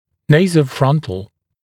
[ˌneɪzəu’frʌntl][ˌнэйзоу’франтл]носолобный